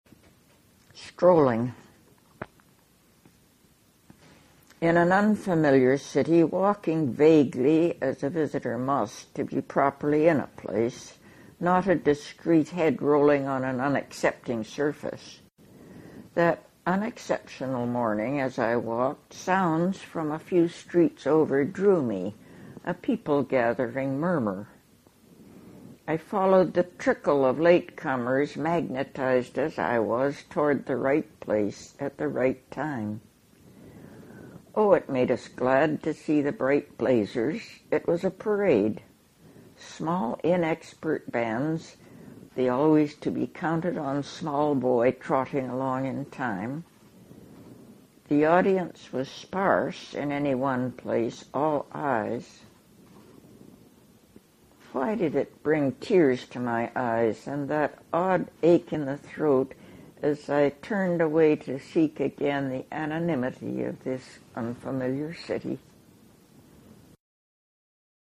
Margaret Avison reads Strolling from Always Now (with permission from The Porcupine's Quill)